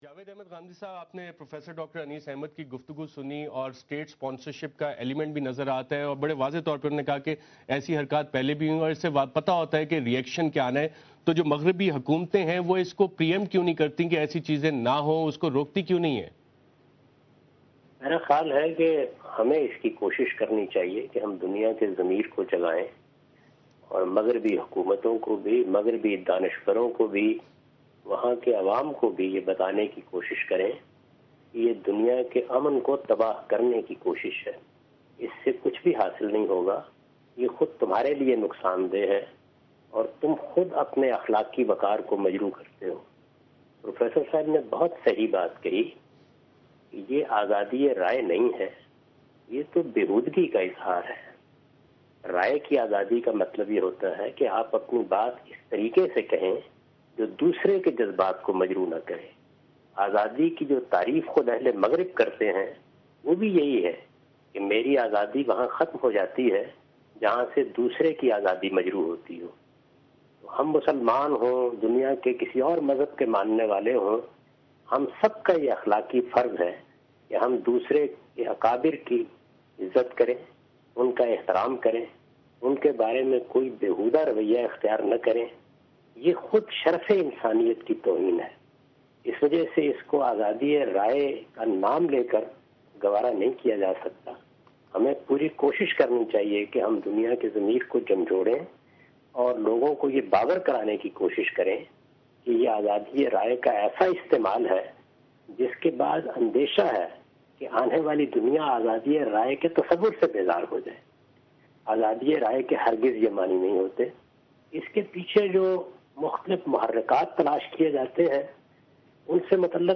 In this video Javed Ahmad Ghamidi answer the question about "Muslims' Reaction to Blasphemy"
دنیا نیوز کے اس پروگرام میں جاوید احمد غامدی "توہین رسالت پر مسلمانوں کا رد عمل" سے متعلق ایک سوال کا جواب دے رہے ہیں